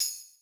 RX5 TAMBRINE.wav